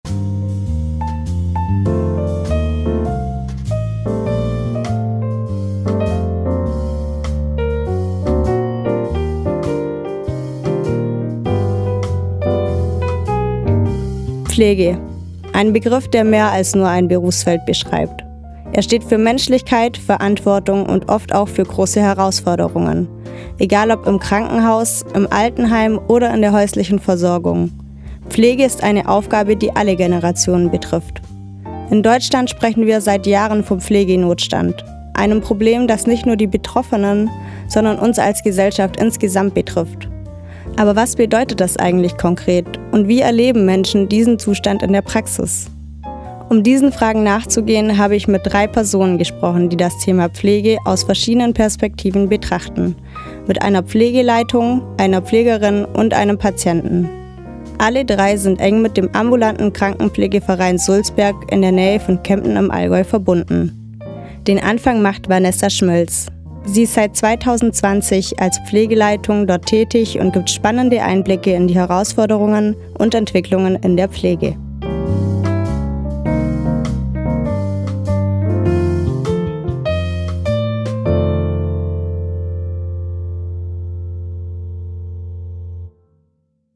Radio Micro-Europa: Sendung (632): „Pflegenotstand“, am Sonntag den 13. Juli 2025  von 12-13 Uhr im Freien Radio Wüste Welle 96,6 – Kabel: 97,45 Mhz, auch als Live-Stream im Internet.